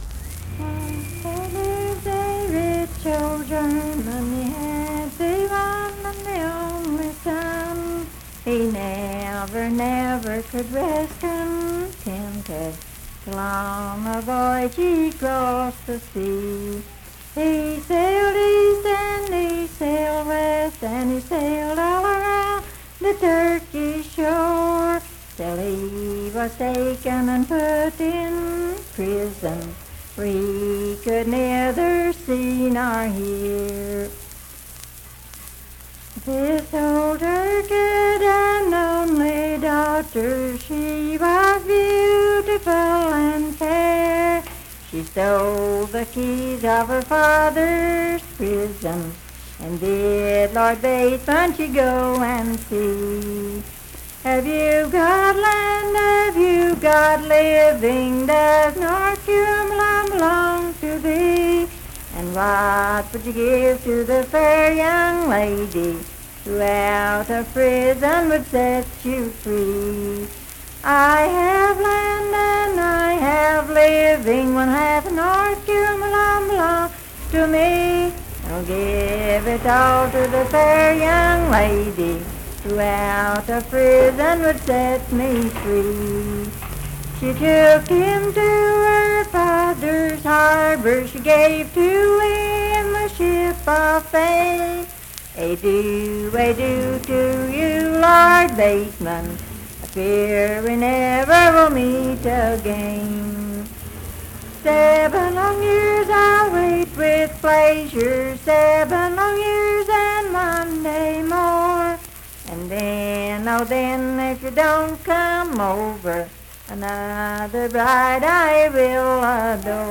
Unaccompanied vocal music
Verse-refrain 16(4).
Voice (sung)
Spencer (W. Va.), Roane County (W. Va.)